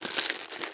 vending2.wav